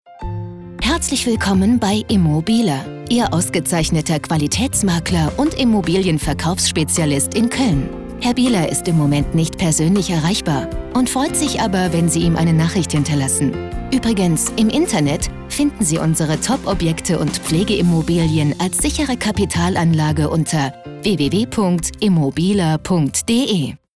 Telefonansage Immobilien – Makler
Mailboxansage – ImmoBiehler – Köln